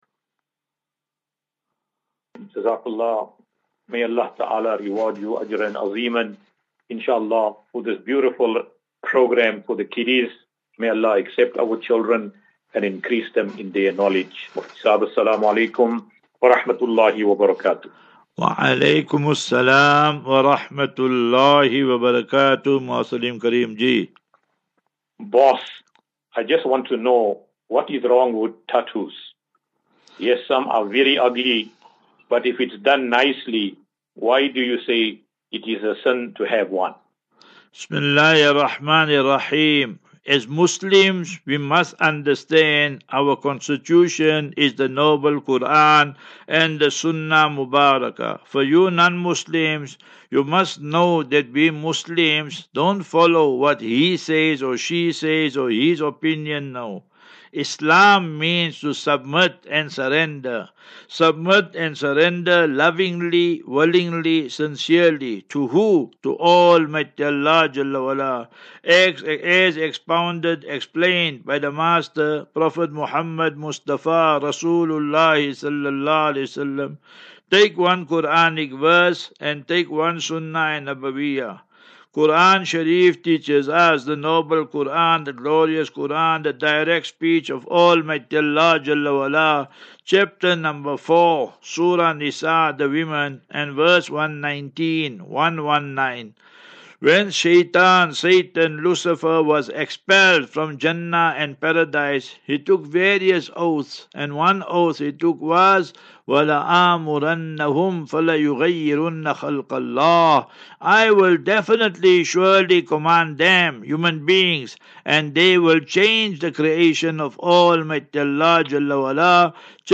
View Promo Continue Install As Safinatu Ilal Jannah Naseeha and Q and A 4 Apr 04 April 2024, Assafinatu - Illal - Jannah.